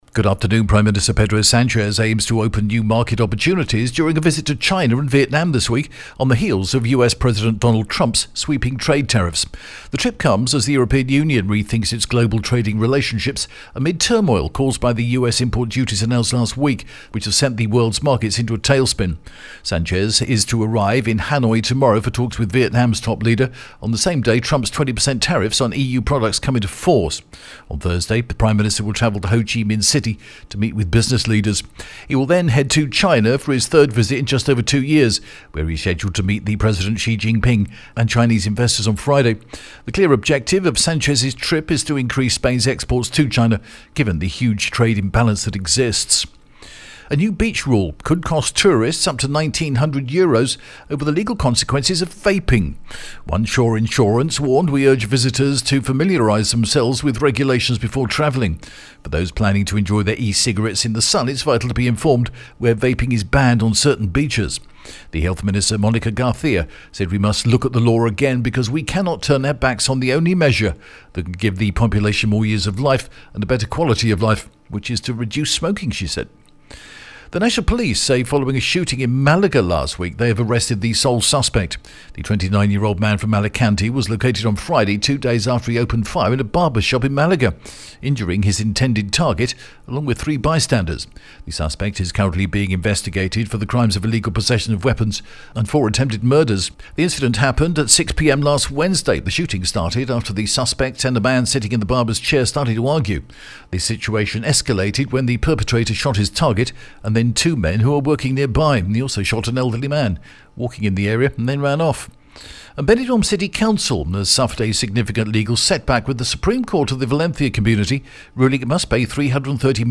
The latest Spanish news headlines in English: April 8th 2025